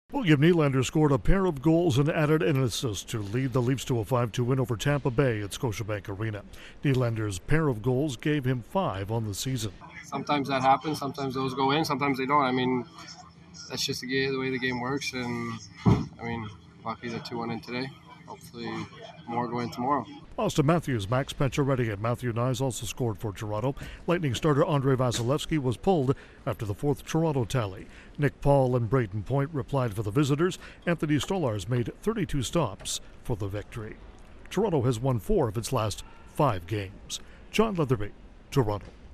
The Maple Leafs bounce back from Saturday's loss to the Rangers. Correspondent